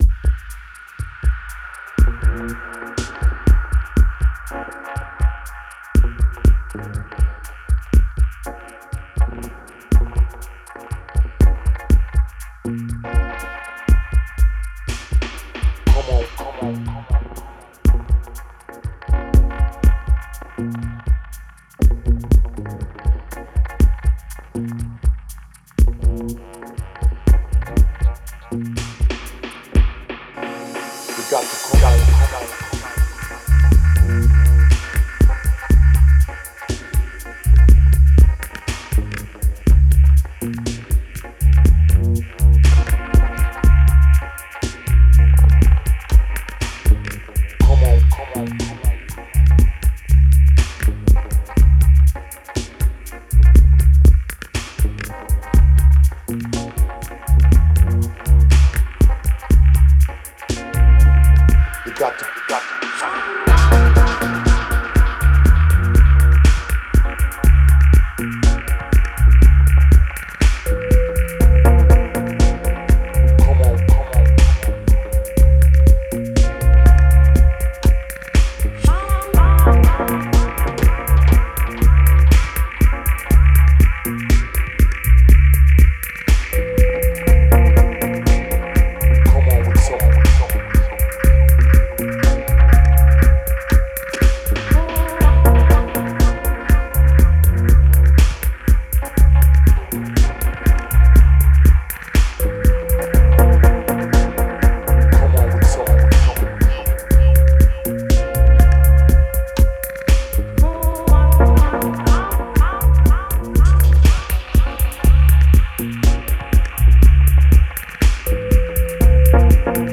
Genre: Downtempo, Dub.